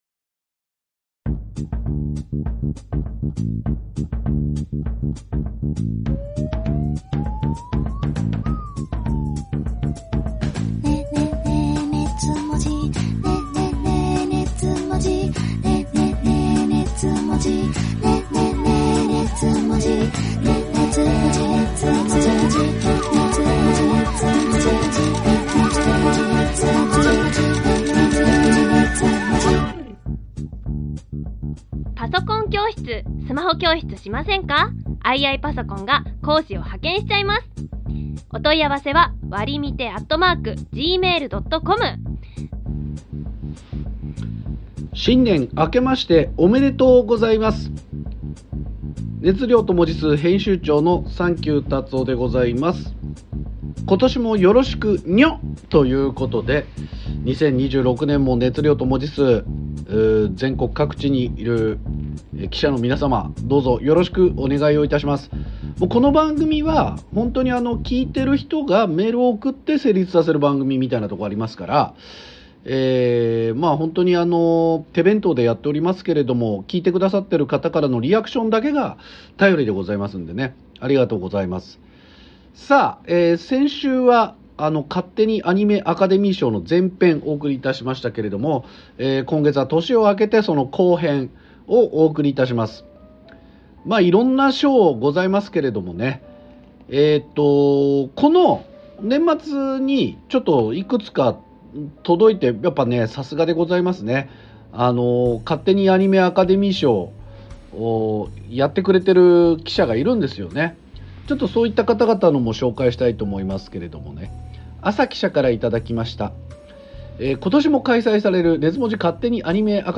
二次元を哲学するトークバラエティ音声マガジン